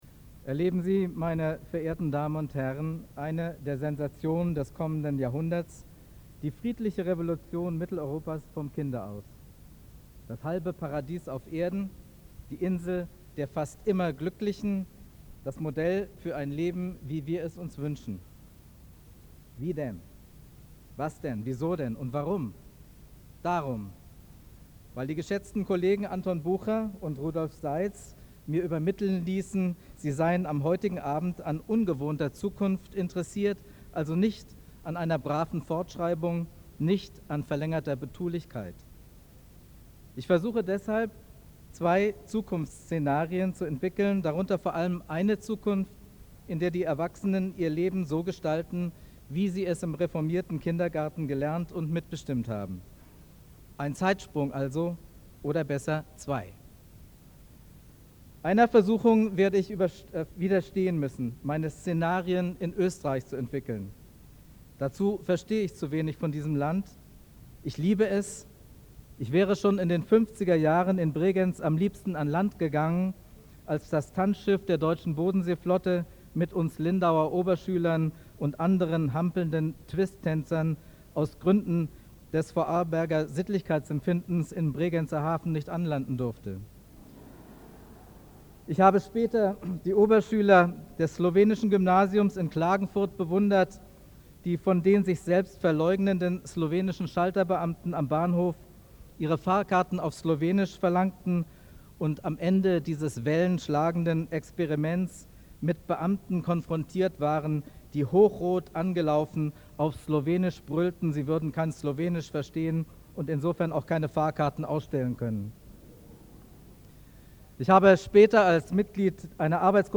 Int. Päd. Werktagung 2000, Salzburg, Große Aula